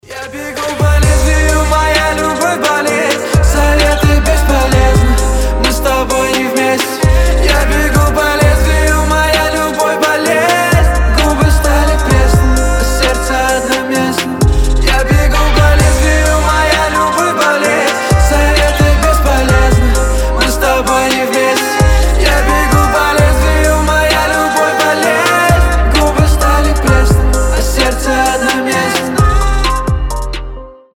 • Качество: 320, Stereo
лирика